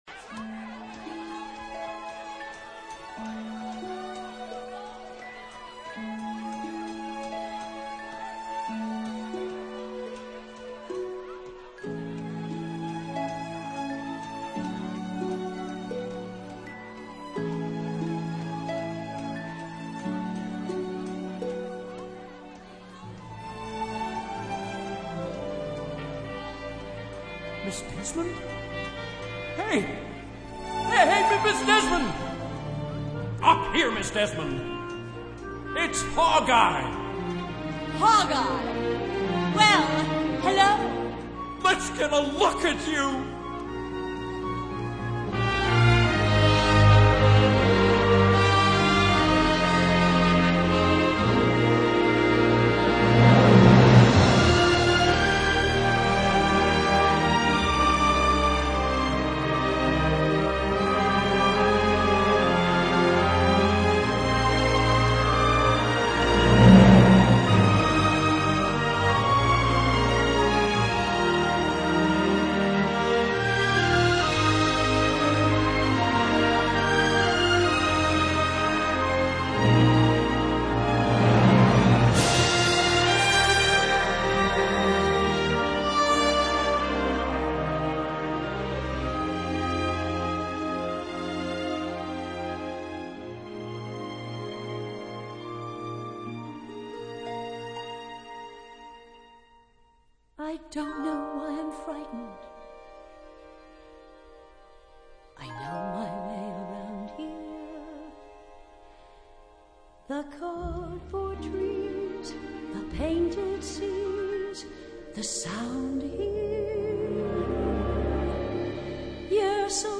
這個 BIGGER 唱得真是大聲 ^_^